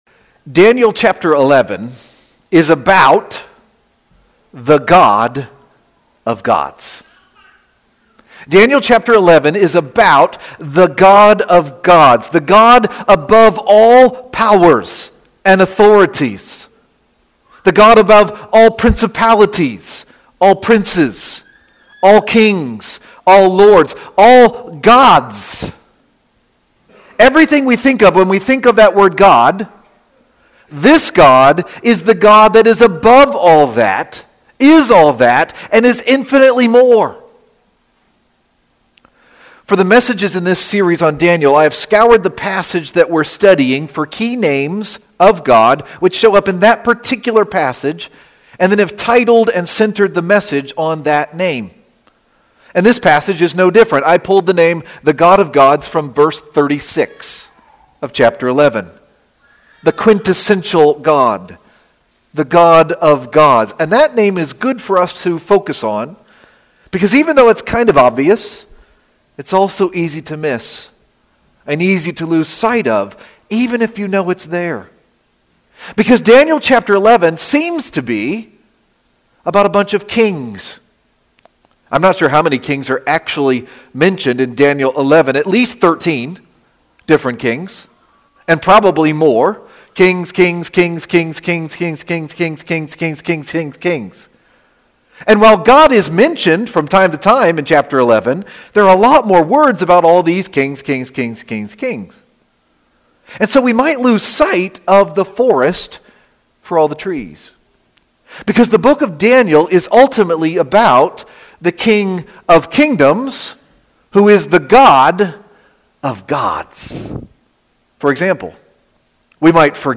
the sermon